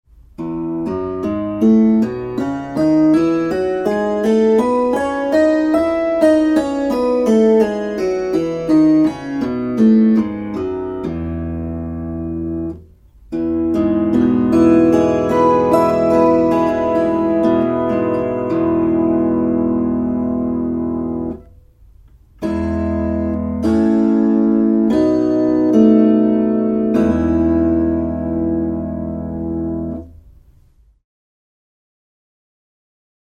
opetuseduuri
eduuri.mp3